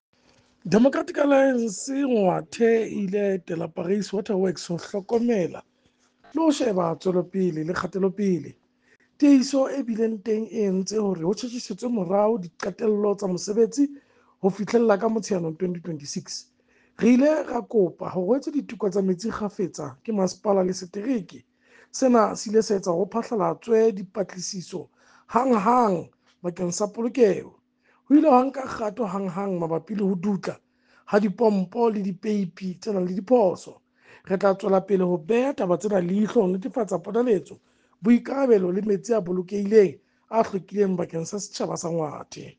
Sesotho soundbite by Cllr Kabelo Moreeng.